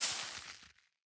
bonemeal1.ogg